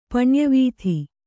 ♪ paṇya vīthi